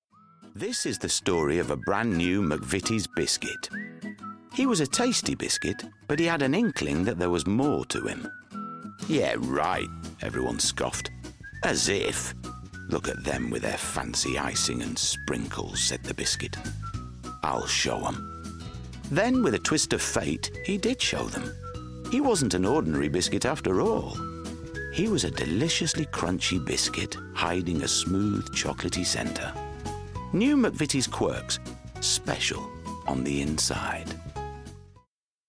Yorkshire
Male
Deep
Dry
Gravelly
MCVITIES COMMERCIAL